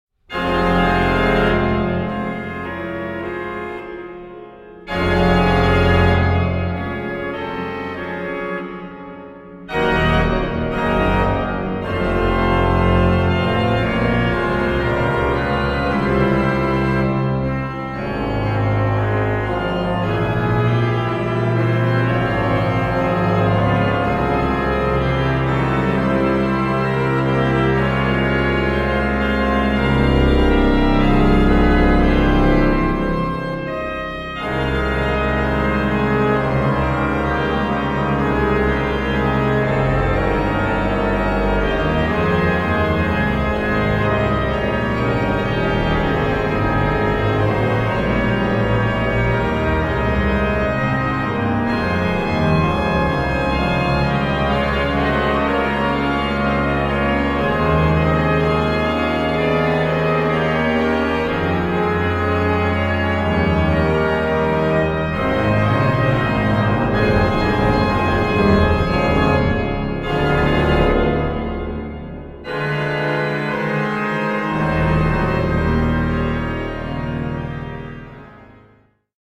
Orgelmusik aus Klassik und Frühromantik